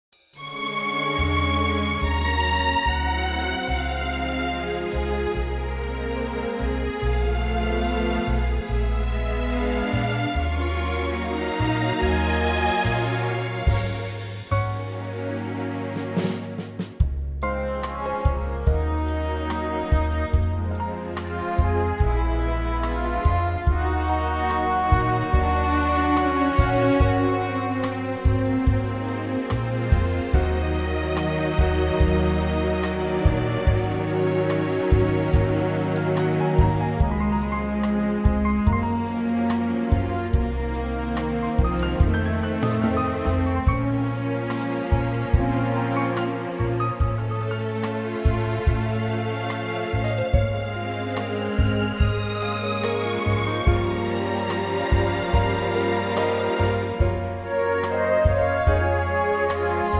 key - Db - vocal range - F to Ab
Very lovely orchestral arrangement